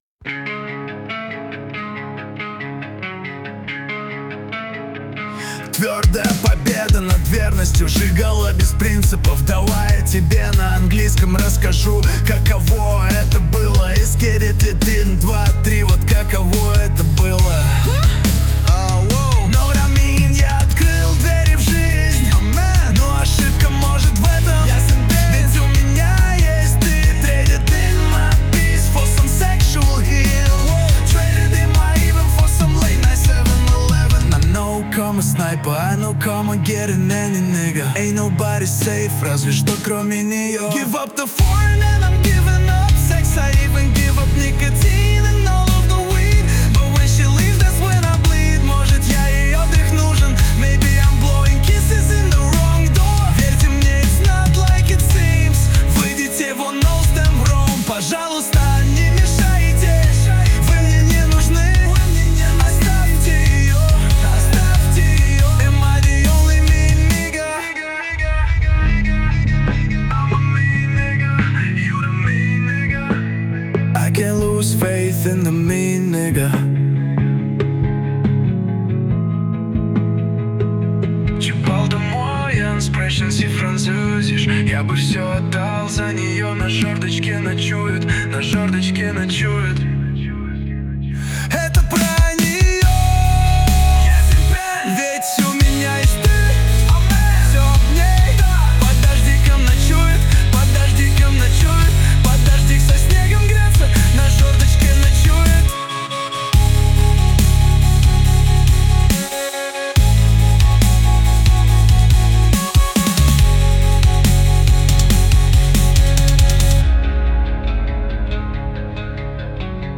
RUS, Lyric, Rap | 17.03.2025 16:32